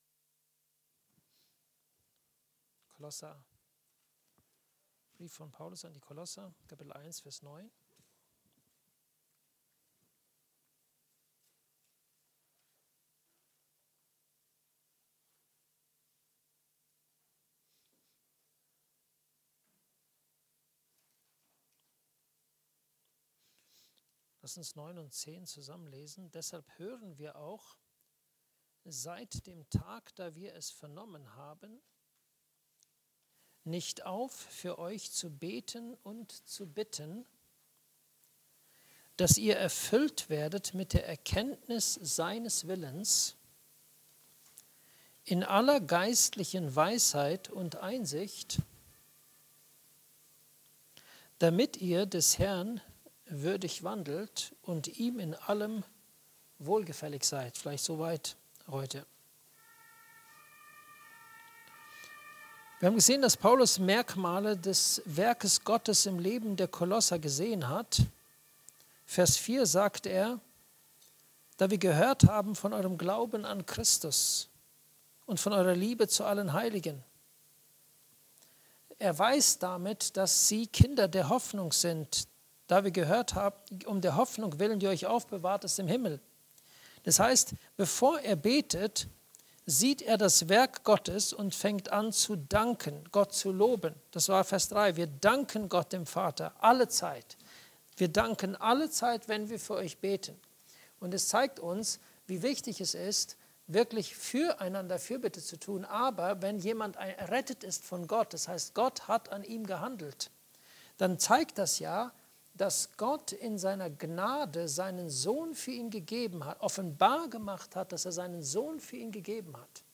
Erfüllt mit der Erkenntnis des Willens Gottes (Andacht Gebetsstunde)